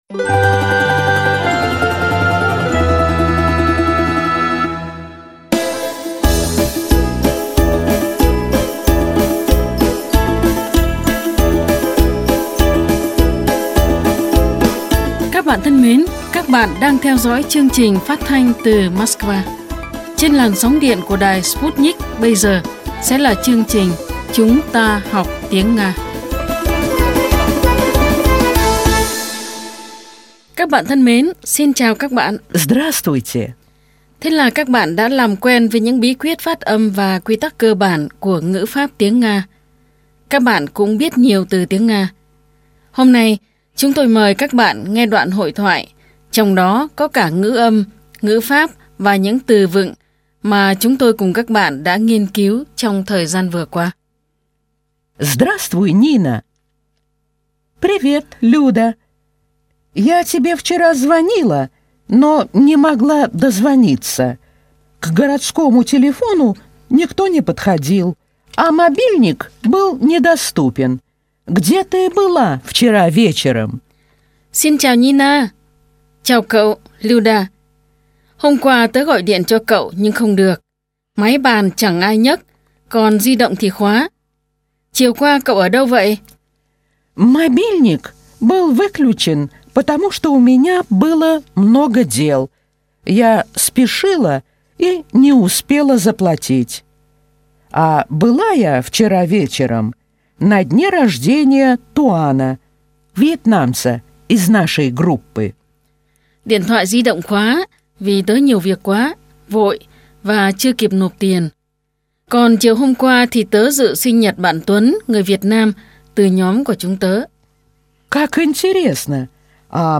Bài 92 – Bài giảng tiếng Nga
Nguồn: Chuyên mục “Chúng ta học tiếng Nga” đài phát thanh  Sputnik